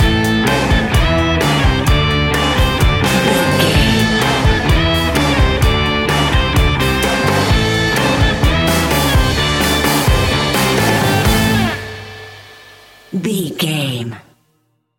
Ionian/Major
D♭
hard rock
heavy rock
distortion